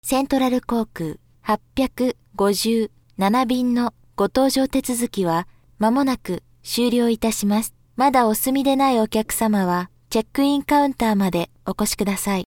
/ M｜他分類 / L50 ｜ボイス
b 3 空港 搭乗アナウンス